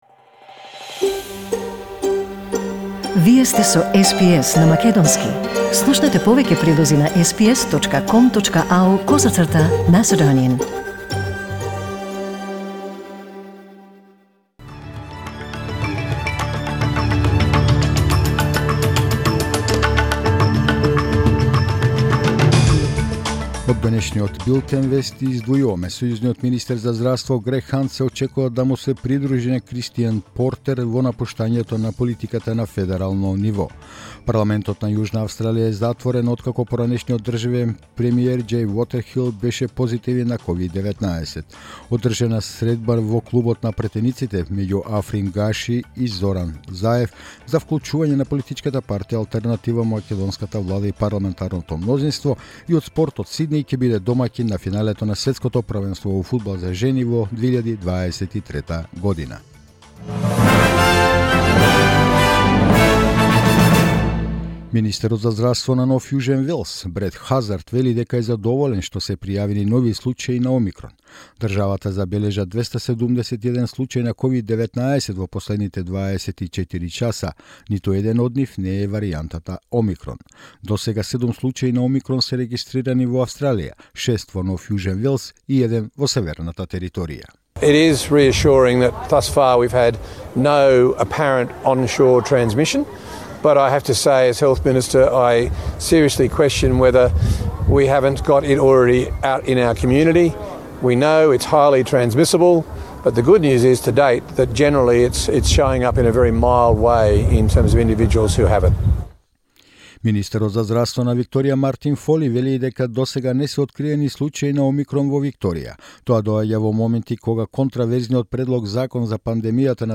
SBS News in Macedonian 2 December 2021